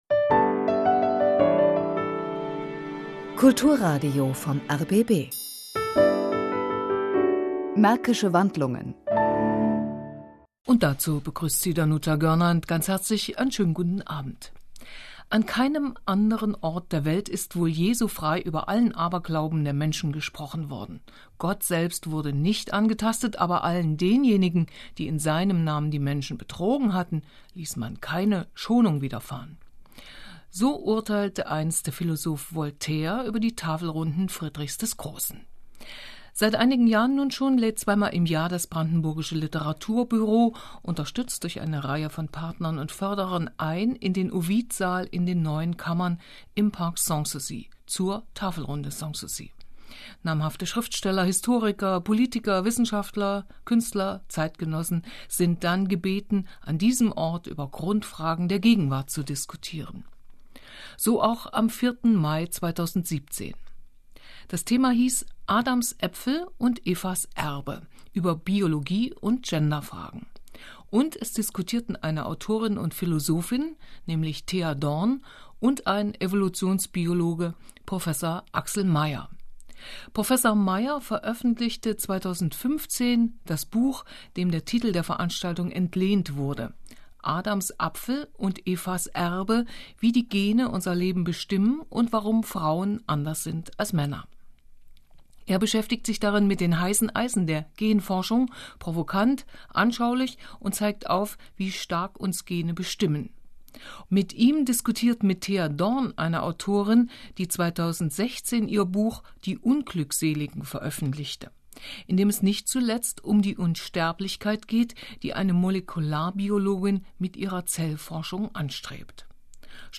Hören Sie hier den leicht gekürzten Mitschnitt des rbb-kulturradios, heute rbb-kultur .